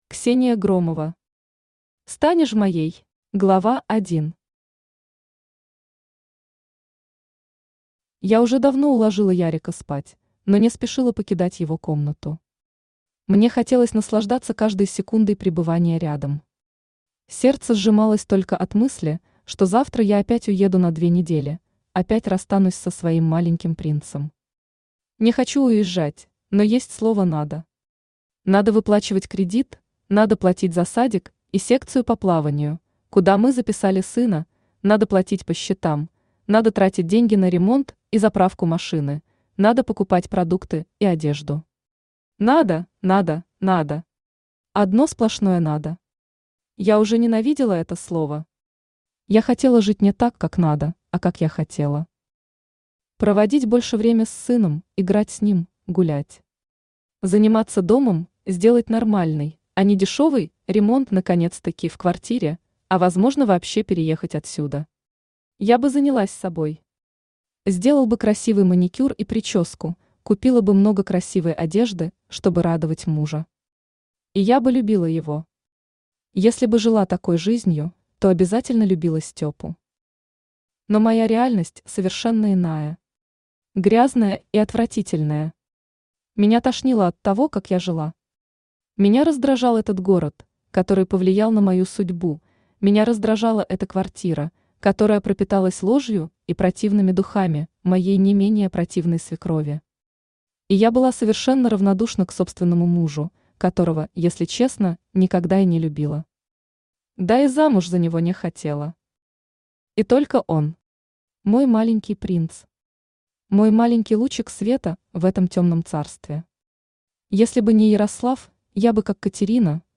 Аудиокнига Станешь моей | Библиотека аудиокниг
Aудиокнига Станешь моей Автор Ксения Громова Читает аудиокнигу Авточтец ЛитРес.